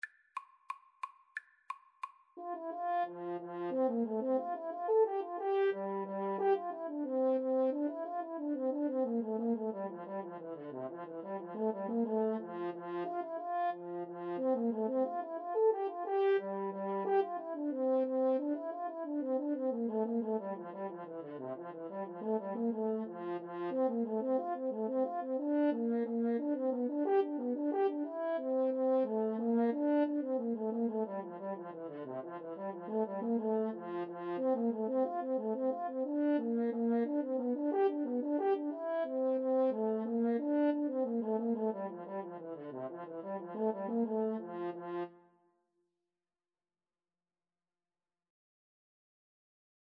2/2 (View more 2/2 Music)
F major (Sounding Pitch) (View more F major Music for French Horn-Bassoon Duet )
Traditional (View more Traditional French Horn-Bassoon Duet Music)